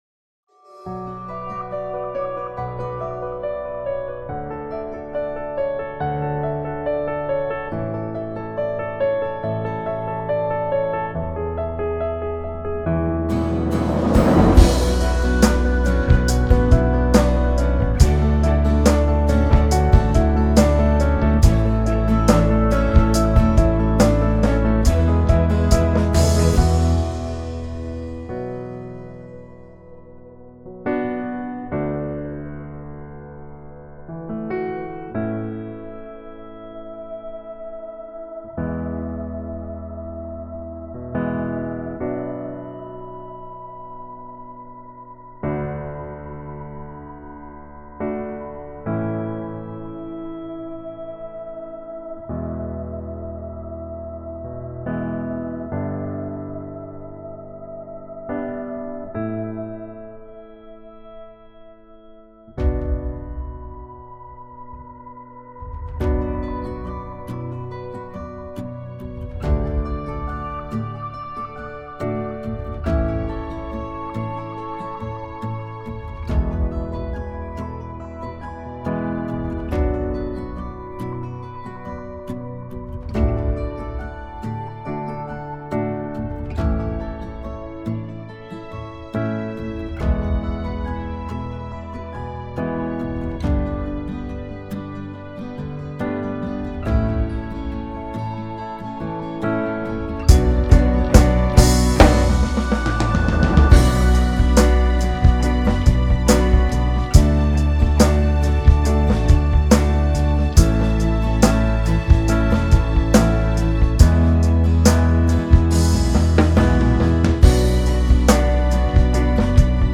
伴奏音樂